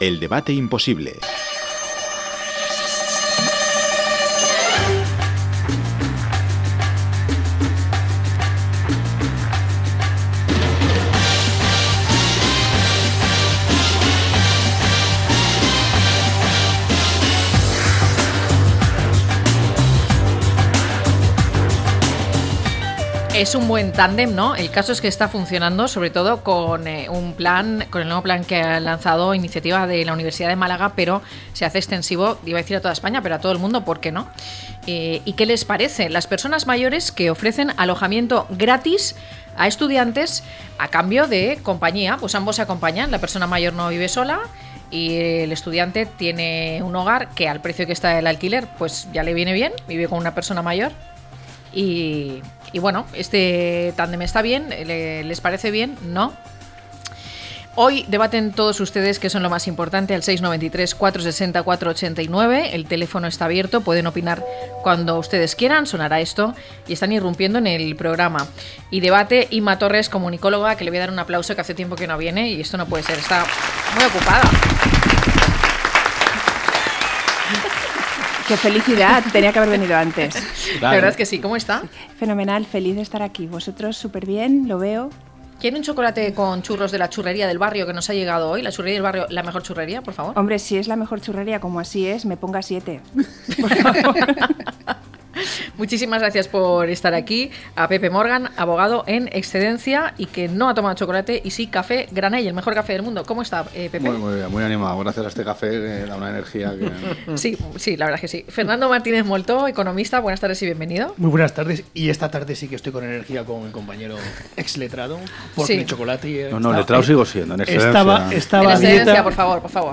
1010-LTCM-DEBATE.mp3